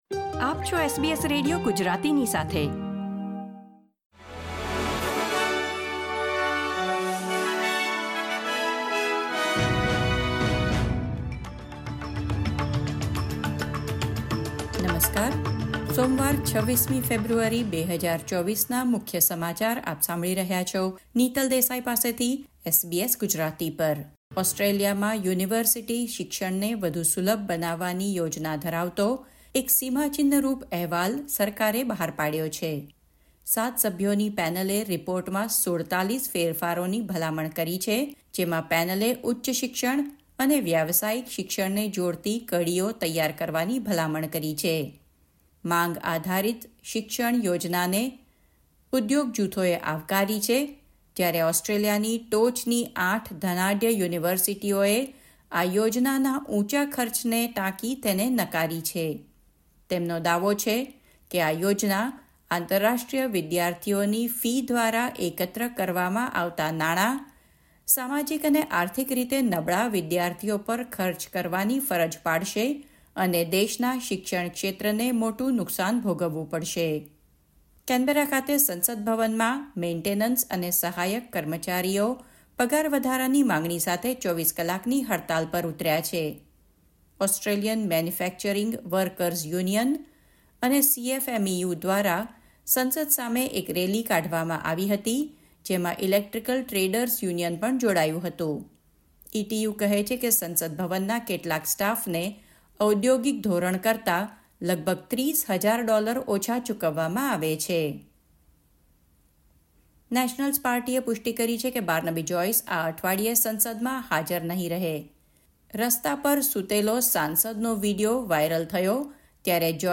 SBS Gujarati News Bulletin 26 February 2024